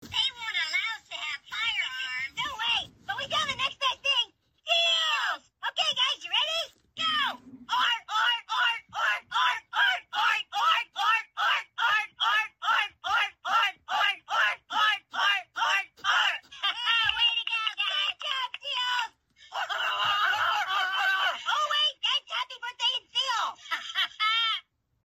Something Really Sealy is a hoops&yoyo greeting card with sound made for birthdays.
Card sound